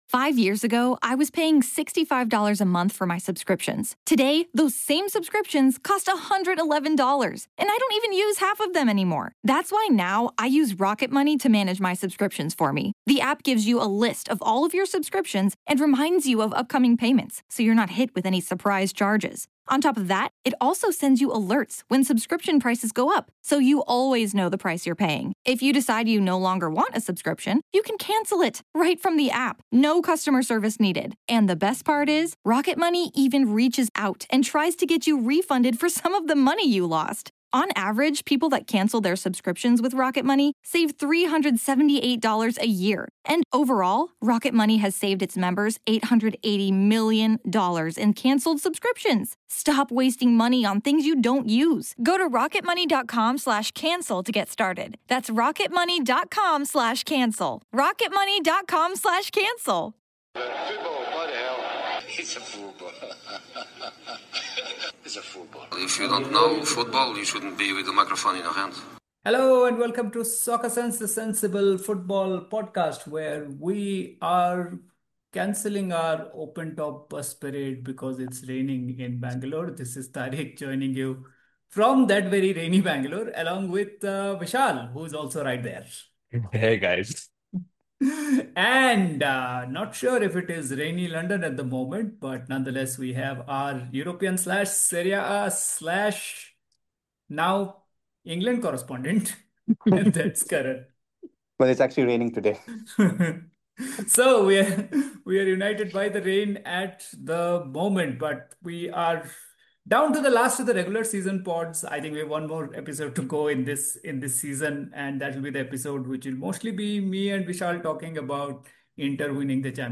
a full panel pod about the joys of finishing on the pinnacle of English football positions: 8th, 9th, and 10th. We talk about the beauty of the three Bs - Bournemouth, Brighton, and Brentford - who finished there and why these clubs in and of themselves are fascinating case studies of what the essence of football truly is.